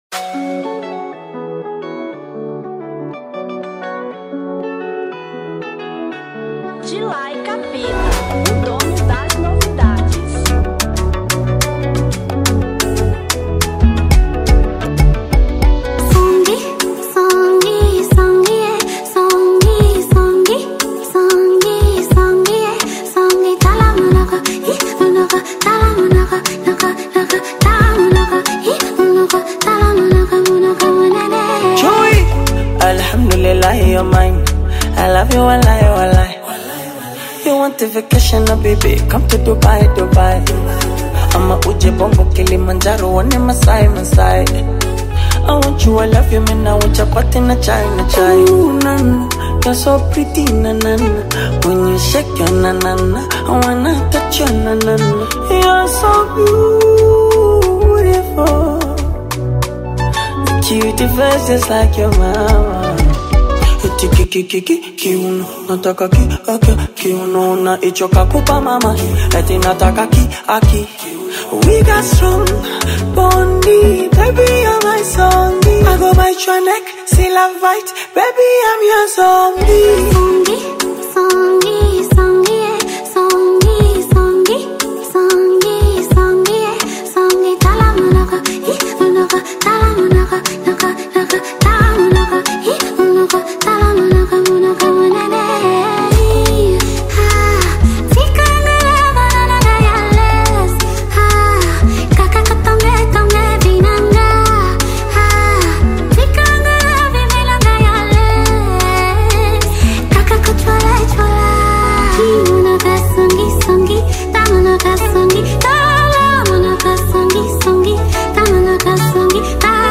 Remix 2025